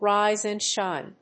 ríse and shíne